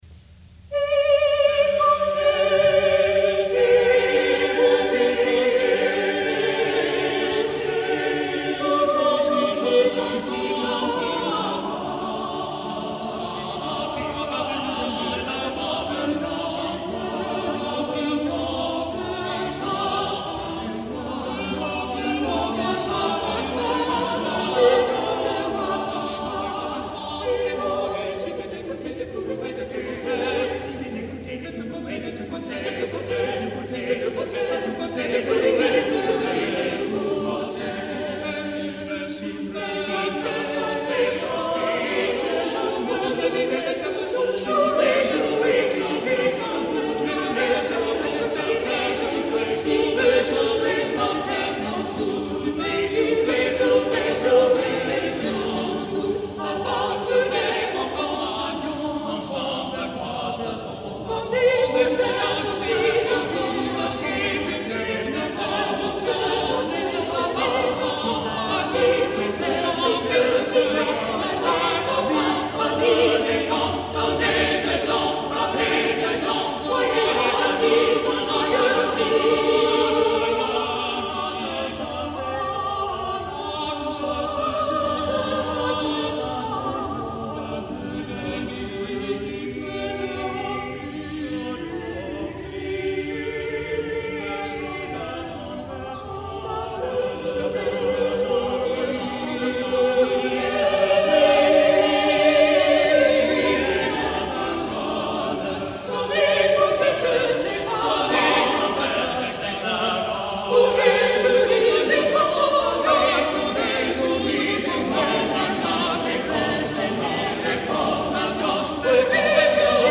| Vocal ensemble 'Ease after Warre' 1977